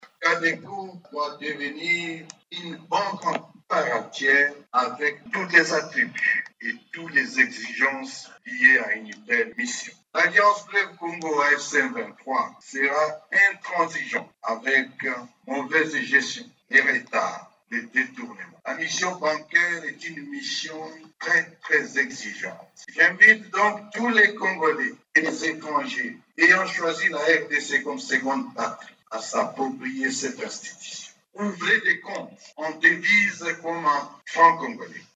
Corneille NANGA , coordonnateur de l'AFC-M23 lors de la cérémonie du lancement des activités de la CADECO dans la ville de Goma
C’était en présence des cadres de l’AFC-M23, des agents de la CADECO ainsi que d’autres personnes venues pour la circonstance.